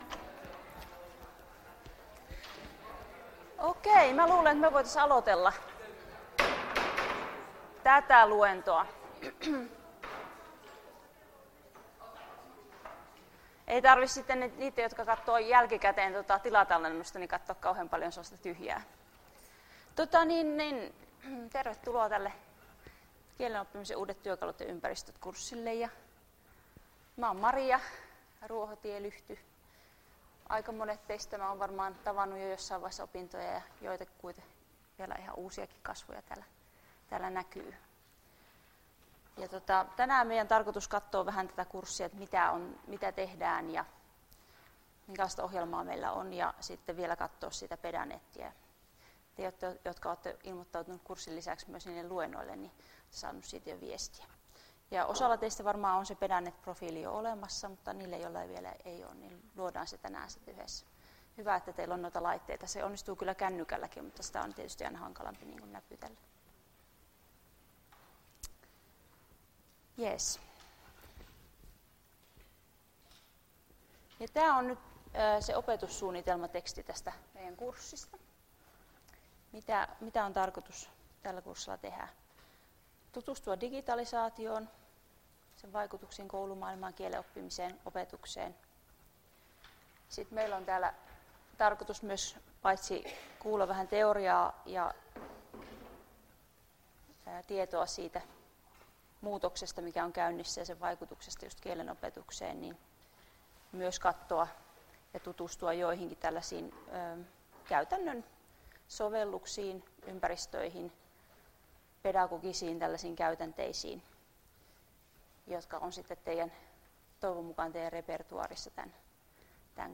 Luento 16.01.2019 — Moniviestin